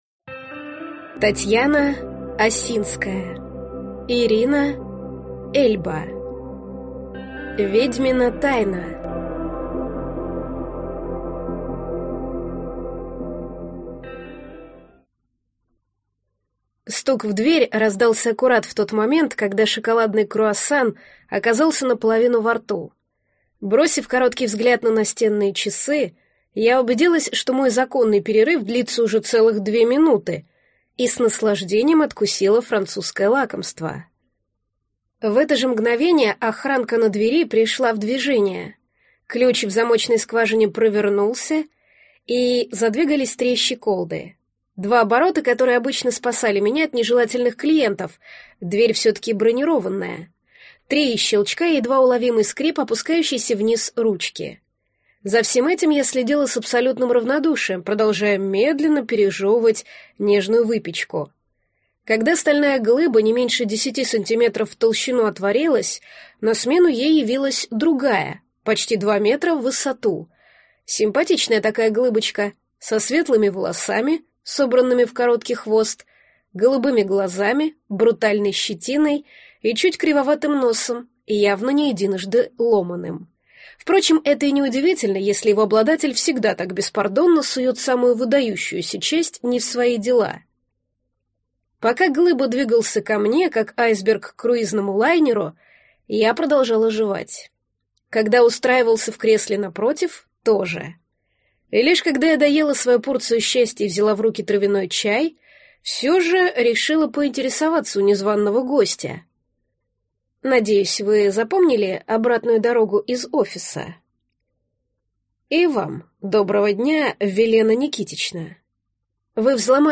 Аудиокнига Ведьмина тайна | Библиотека аудиокниг
Прослушать и бесплатно скачать фрагмент аудиокниги